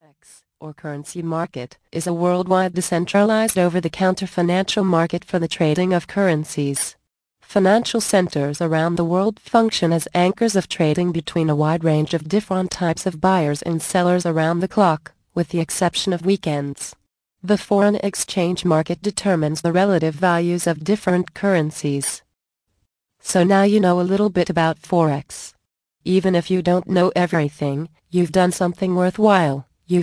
Forex Secrets audio book + FREE Gift